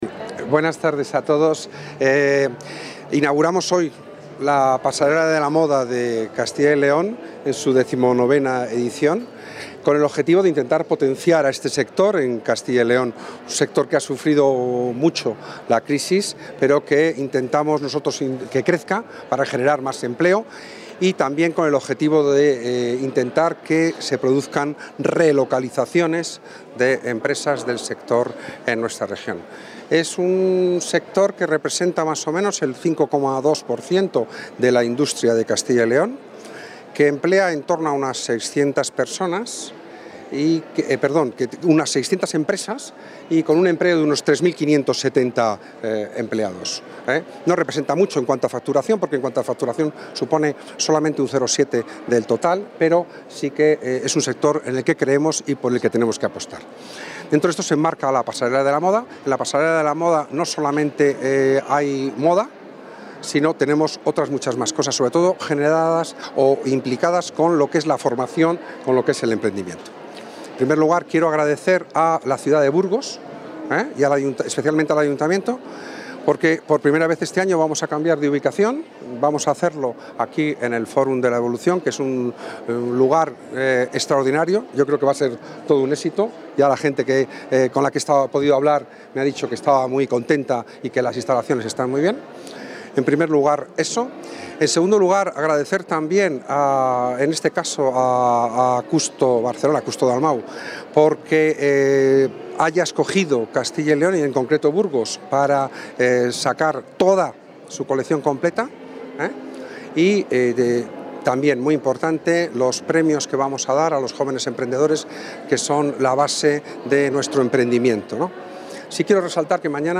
El director general de la Agencia de Innovación, Financiación e Internacionalización Empresarial (ADE), José María Ribot, ha asistido a la jornada inaugural de la XIX Pasarela de la Moda de Castilla y León.